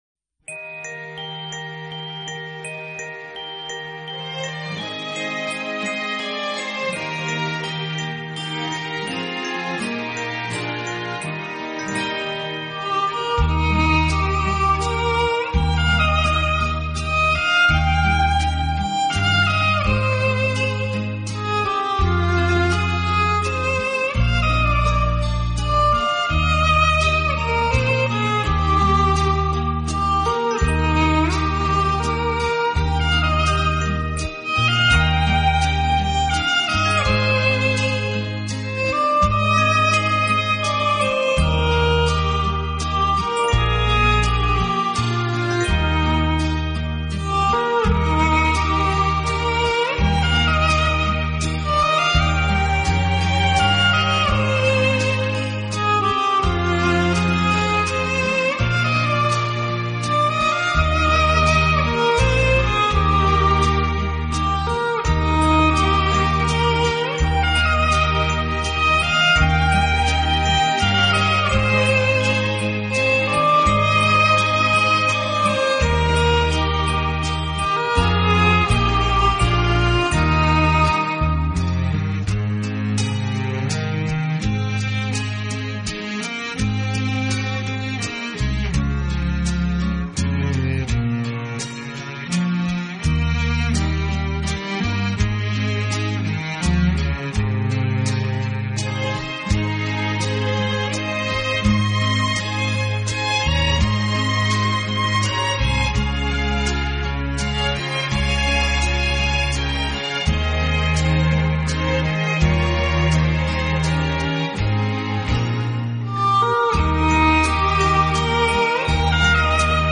【优美小提琴】